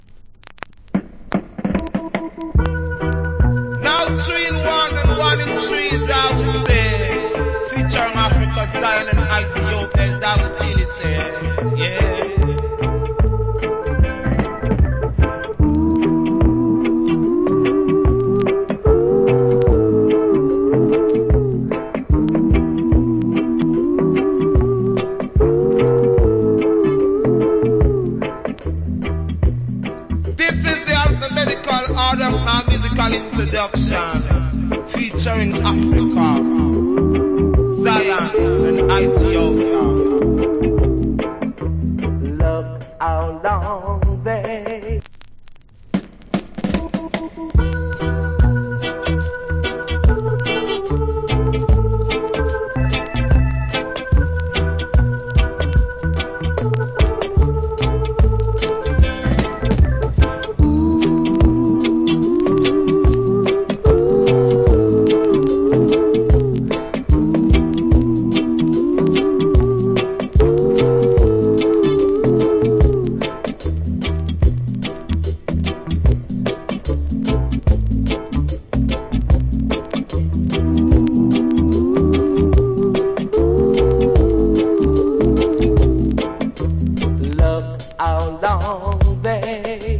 WIcked DJ.